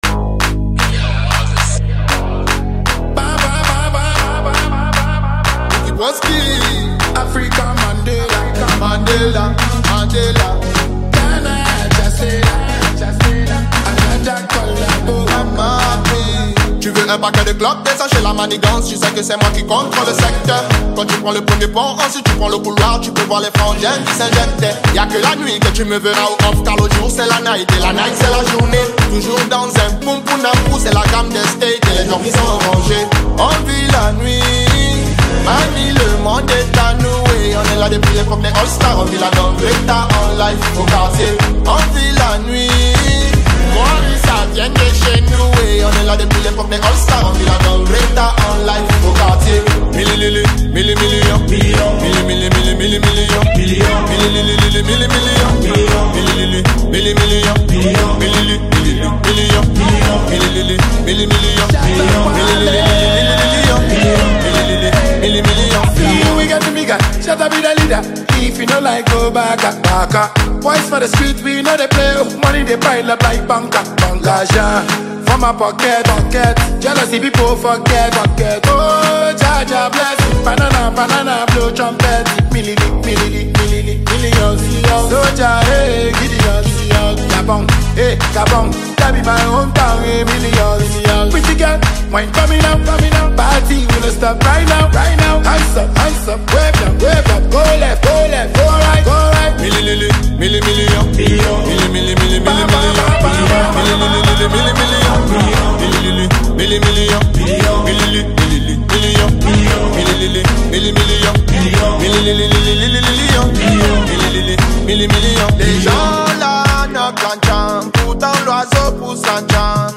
Ghana Music
known for his smooth vocal delivery
energetic and charismatic rap verses